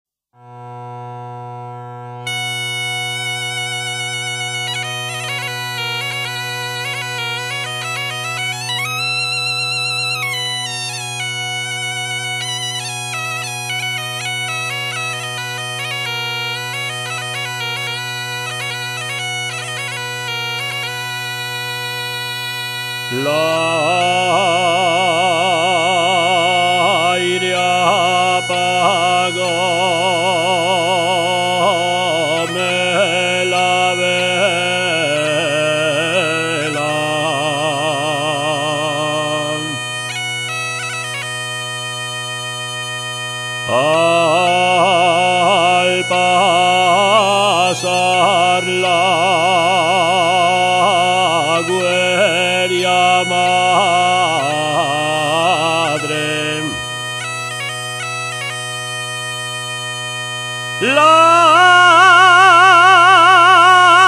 Tonada antigua con gaita.
Gaita